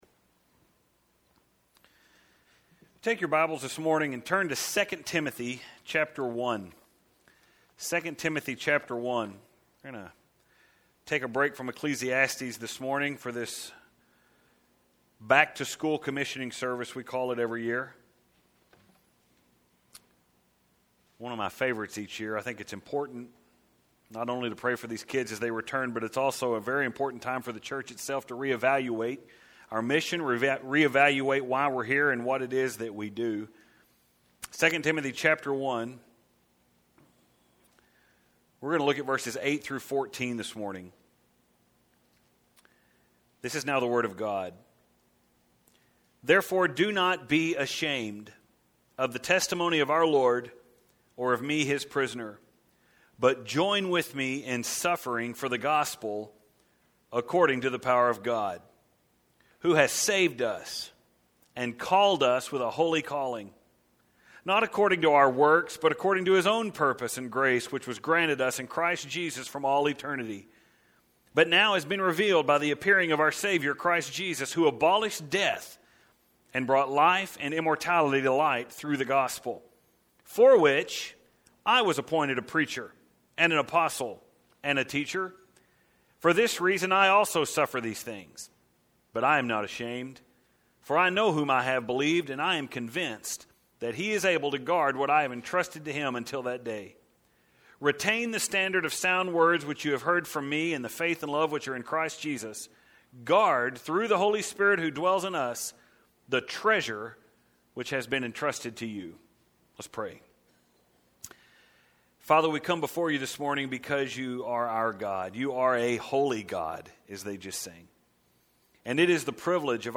It is our annual School Commissioning Service.